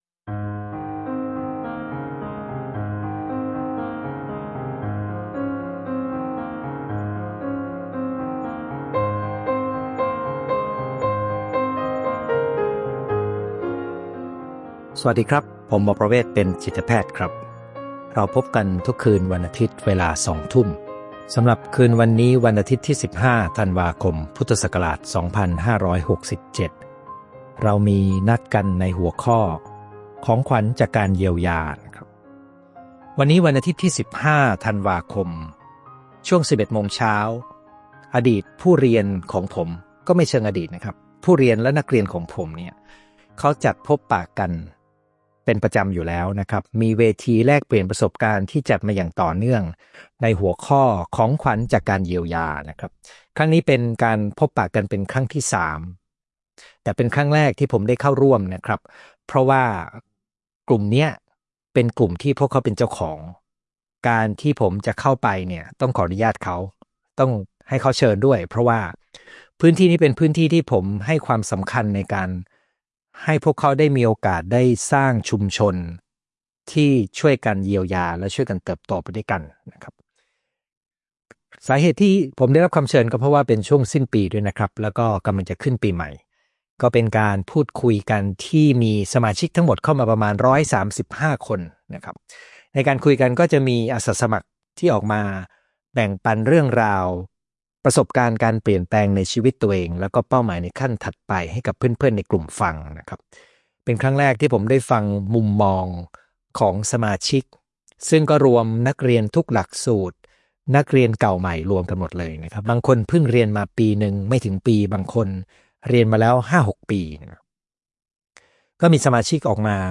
Facebook LIVE